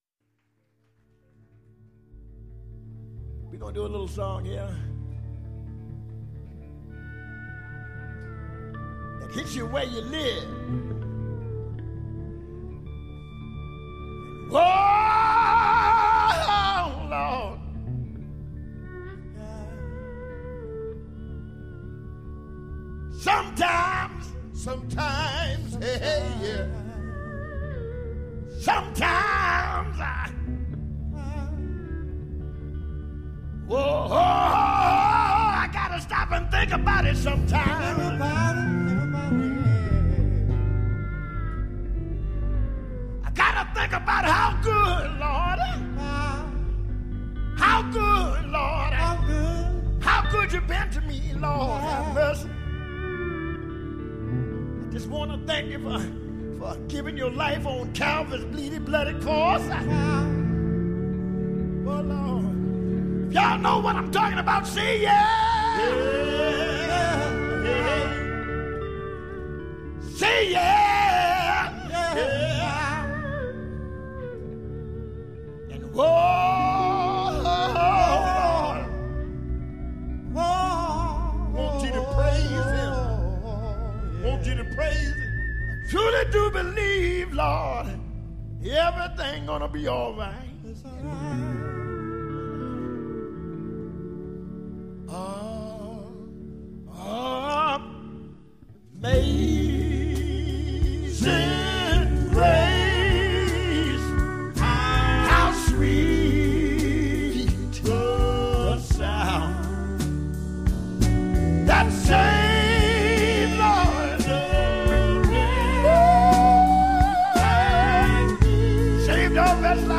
风格流派: Blues & Gospel